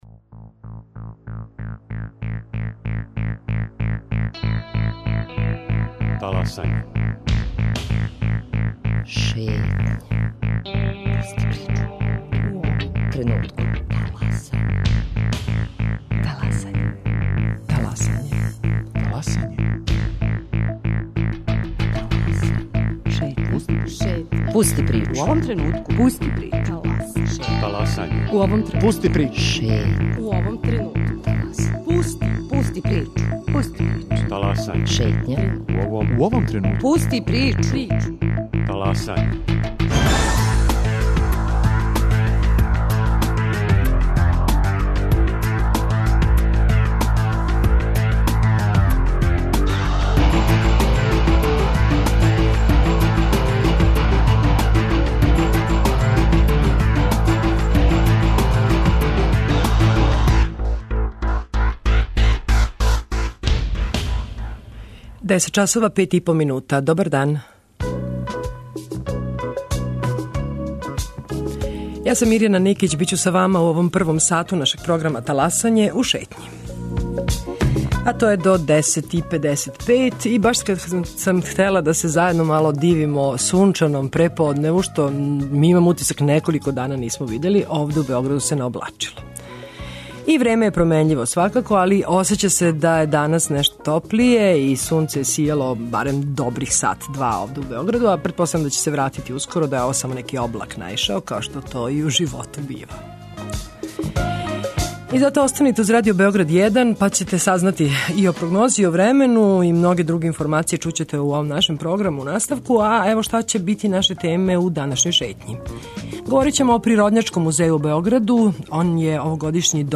Радио Београд 1, 10.00